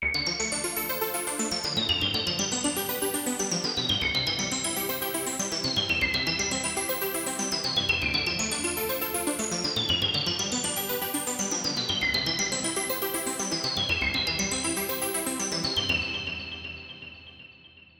Да, вот тут убрал полностью.